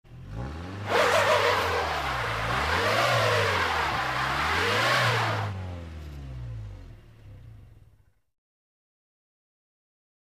Car Tires Snow; Auto Stuck In Snow Tire Spins, Distant Perspective